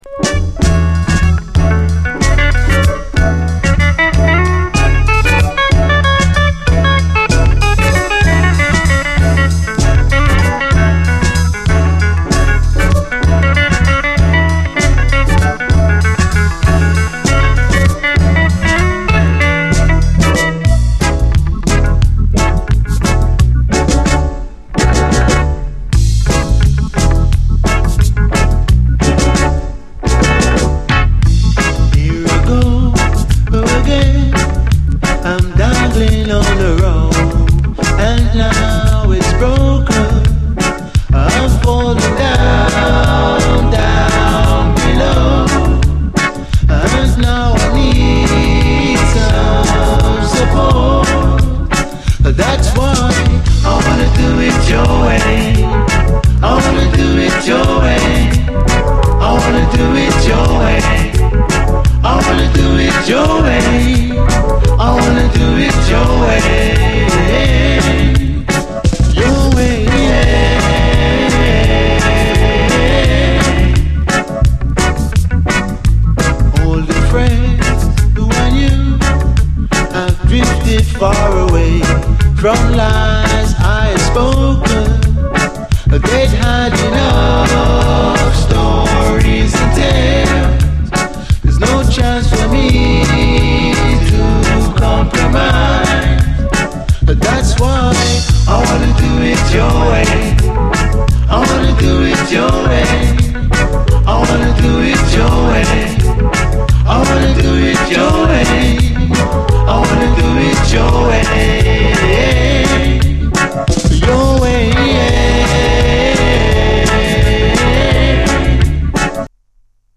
REGGAE
シルキーな男性コーラス、優しく踊れるグレイト・メロウ・ステッパー・ラヴァーズ！